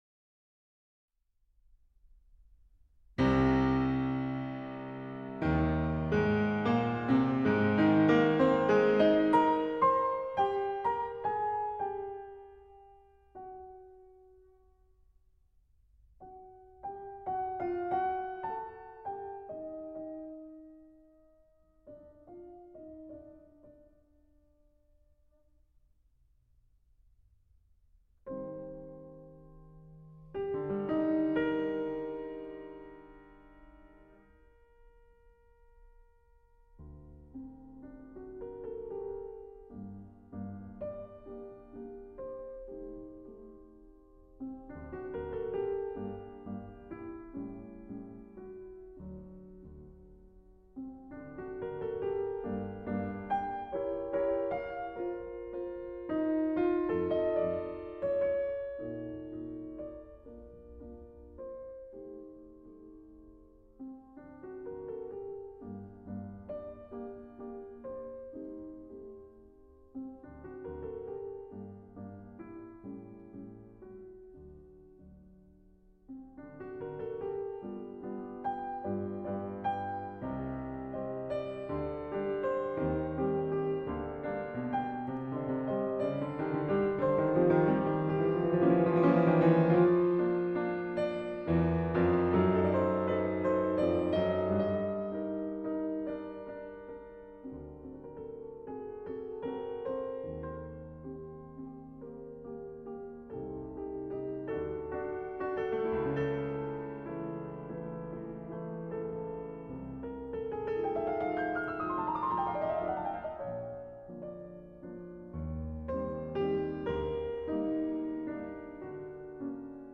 Instrumental ballade